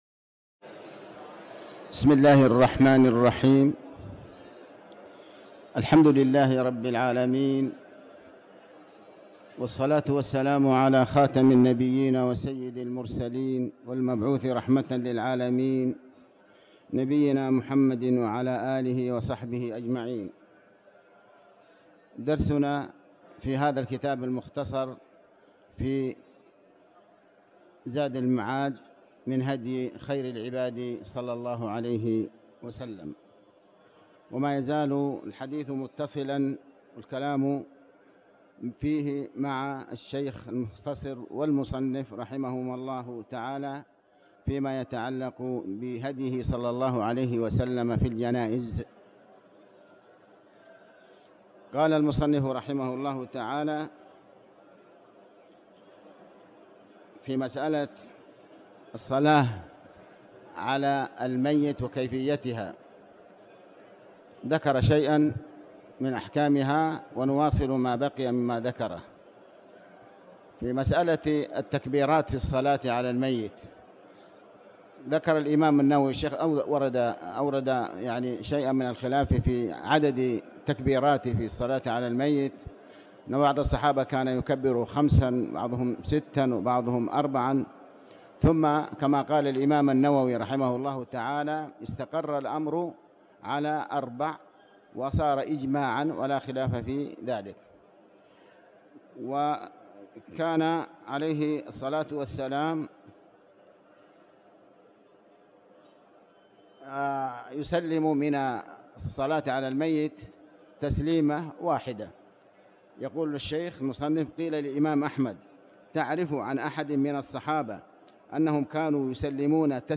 تاريخ النشر ١٥ جمادى الآخرة ١٤٤٠ هـ المكان: المسجد الحرام الشيخ: علي بن عباس الحكمي علي بن عباس الحكمي صلاة الجنائز